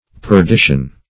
Perdition \Per*di"tion\ (p[~e]r*d[i^]sh"[u^]n), n. [F., fr. L.